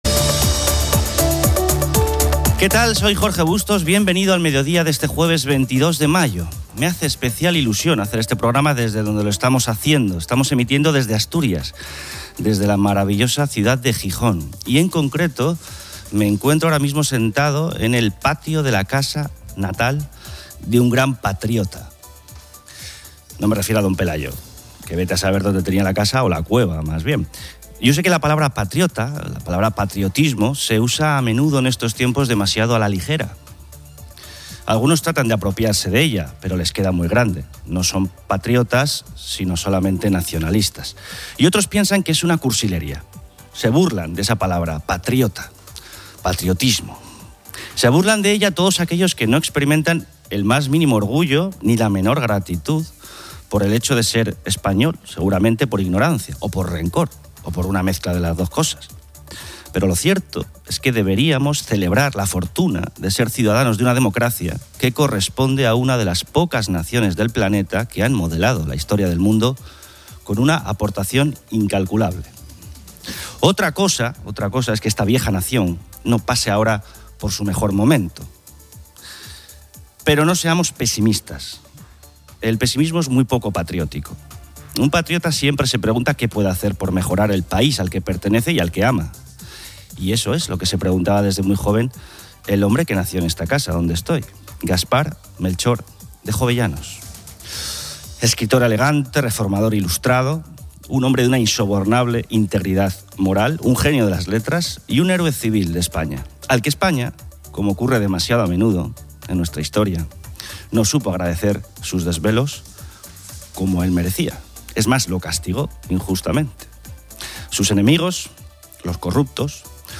Me hace especial ilusión hacer este programa desde donde lo estamos haciendo, estamos emitiendo desde Asturias, desde la maravillosa ciudad de Gijón y en concreto me encuentro ahora mismo sentado en el patio de la casa natal de un gran patriota.